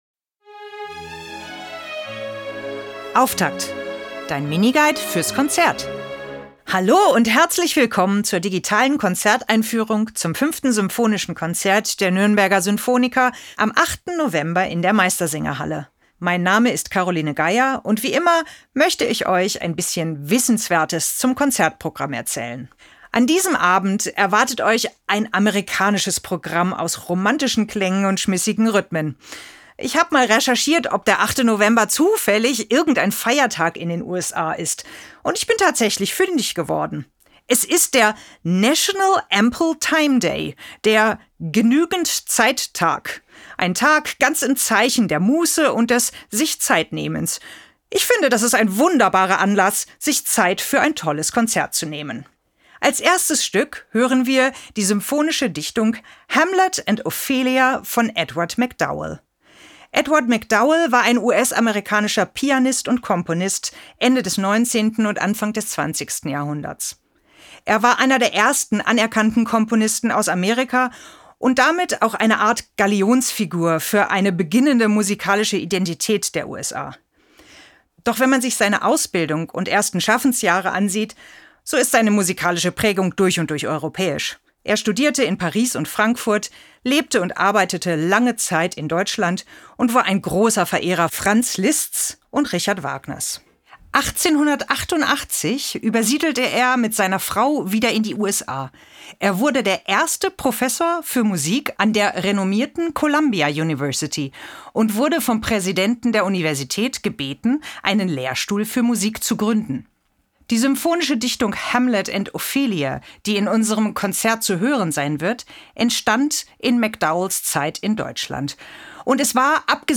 Musik Jingle: Serenade für Streicher, 2. Satz – Tempo di Valse‘